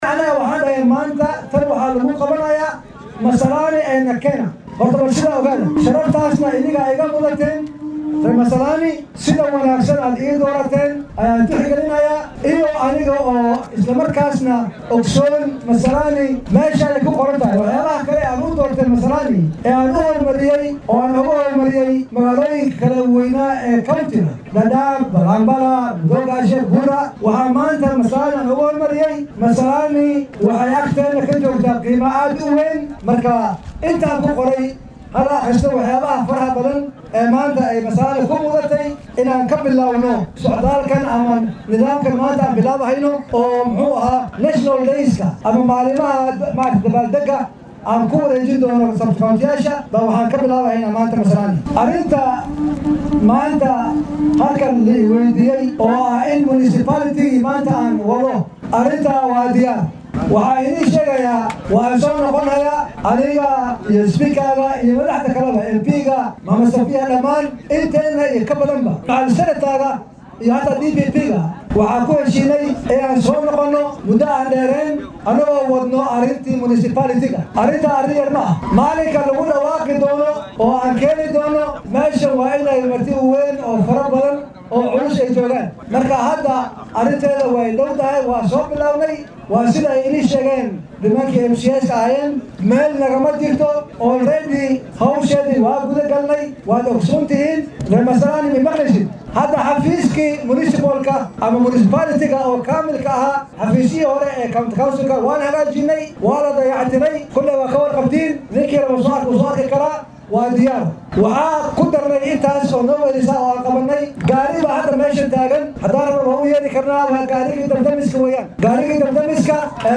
Guddoomiyaha ismaamulka Garissa Nathiif Jaamac Aadan ayaa sheegay in la billaabay qorsho ah in dabbaal degyada qaran sida maalinta Jamhuri Day ee shalay wadanka laga xusay lagu qabto deegaan baarlamaaneedyada. Arrintan ayuu shaaca ka qaaday xilli uu ku sugnaa degmada Masalani ee deegaanka Ijara.